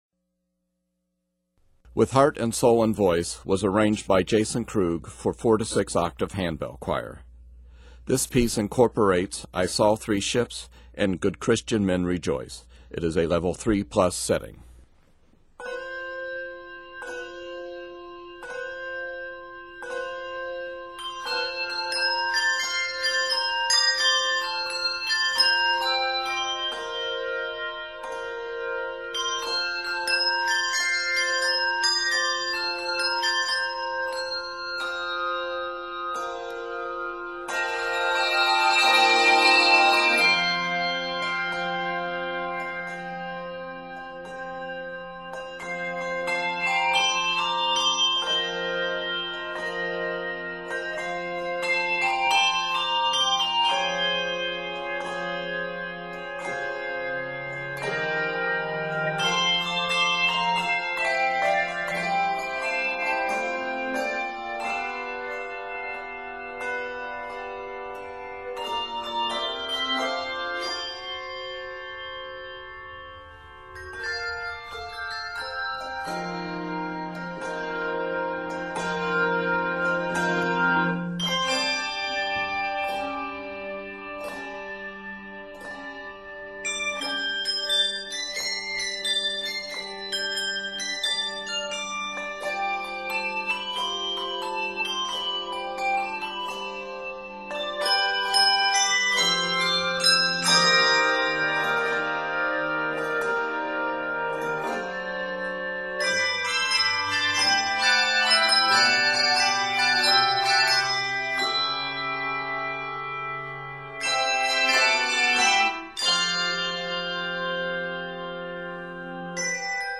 arranged in F Major, G Major, and A Major
Octaves: 4-6